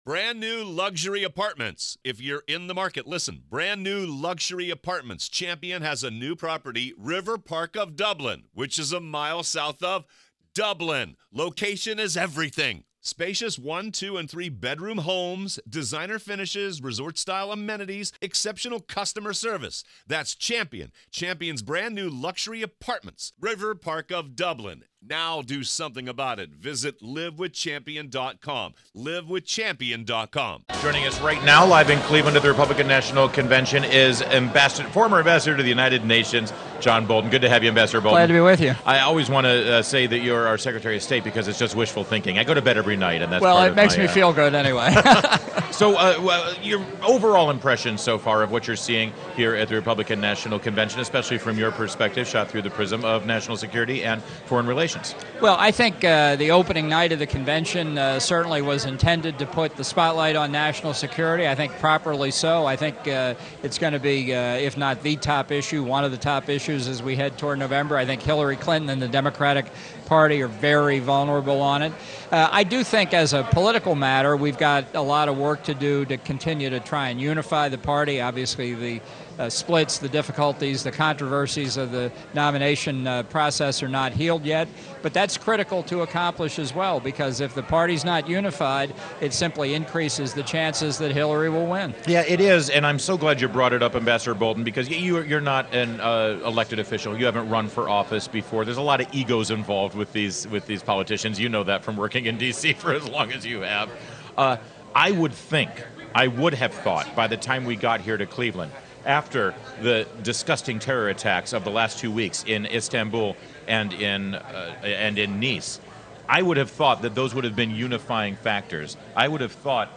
WMAL Interview - Amb. John Bolton - 07.20.16